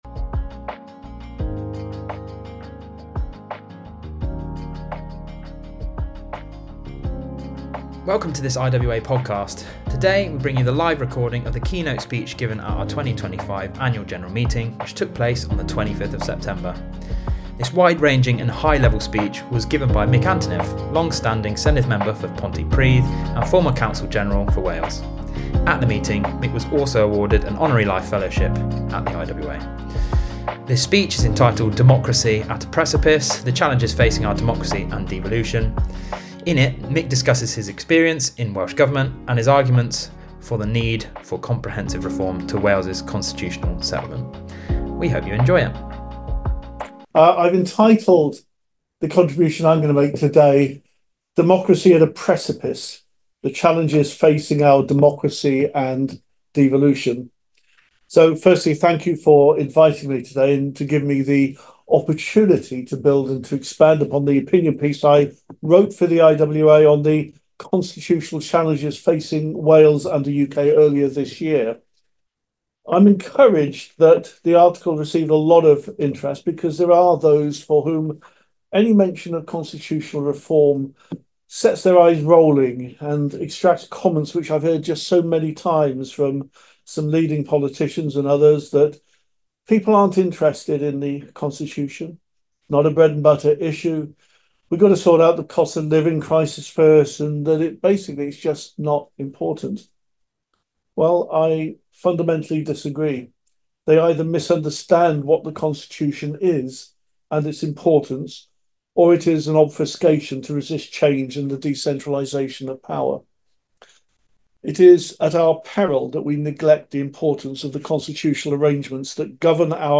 In this episode, we bring you the live recording of the keynote speech given at the IWA's 2025 Annual General Meeting held on the 25th of September.
This wide-ranging and high level speech was given by Mick Antoniw MS, long-standing Senedd Member for Pontypridd and former Counsel General for Wales.